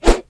gnoll_warror_swish.wav